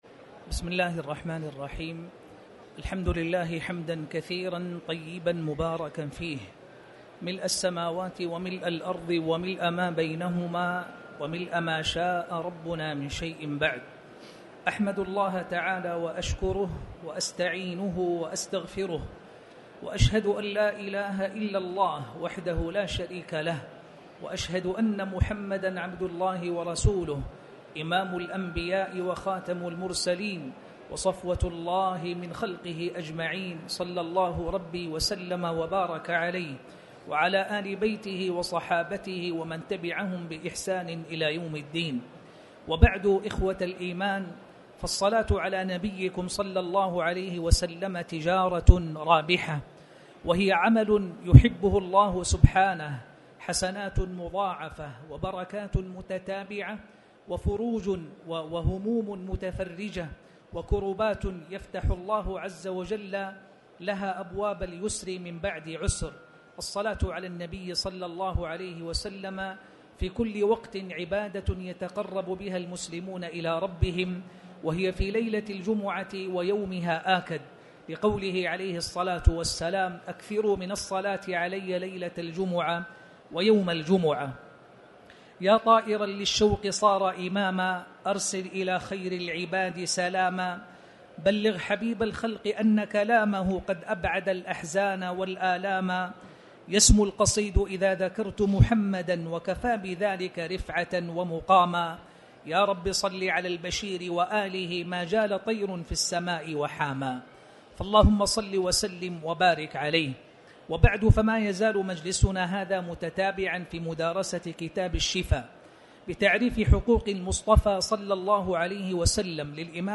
تاريخ النشر ٢٤ شعبان ١٤٣٩ هـ المكان: المسجد الحرام الشيخ